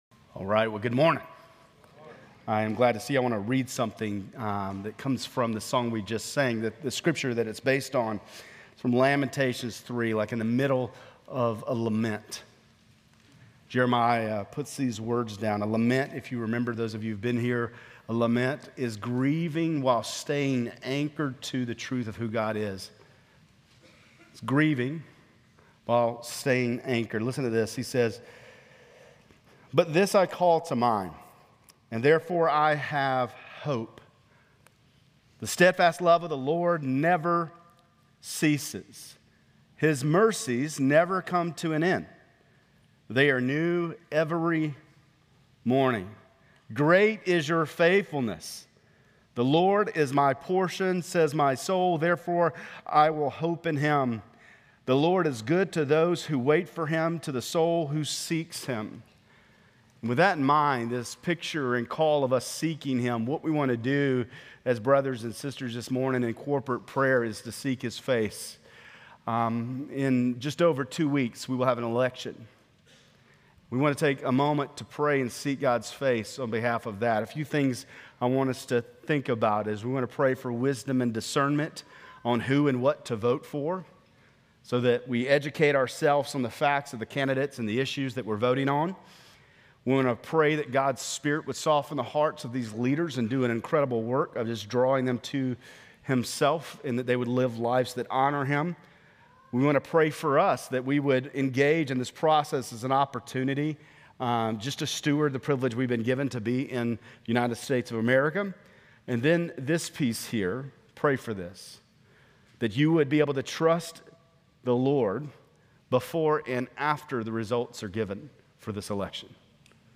Grace Community Church Lindale Campus Sermons Gen 17:15-27 - Isaac Oct 25 2024 | 00:25:21 1x 00:00 / 00:25:21 Subscribe Share RSS Feed Share Link Embed